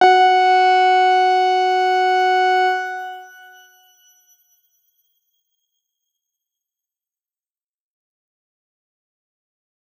X_Grain-F#4-pp.wav